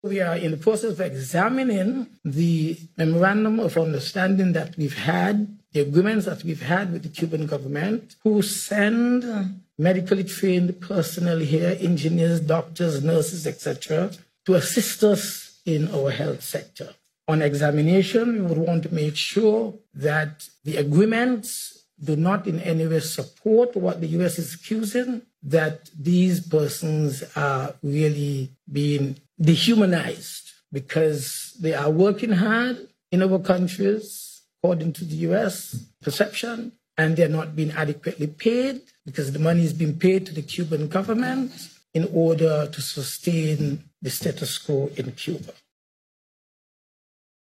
Speaking about the matter on Mar. 6th, Minister of Foreign Affairs, the Rt. Hon. Dr. Denzil Douglas, affirmed that the Cubans are in SKN earning an honest living: